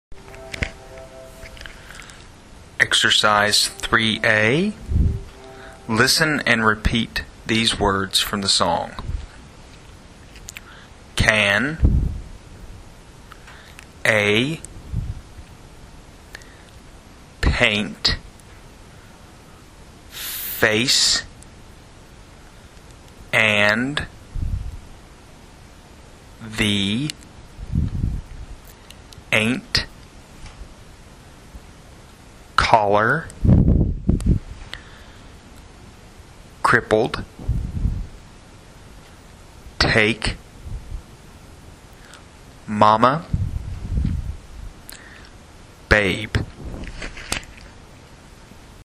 Ressource utilisée: piste son enregistrée par l’assistant sur le baladeur et CD.